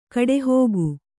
♪ kaḍehōgu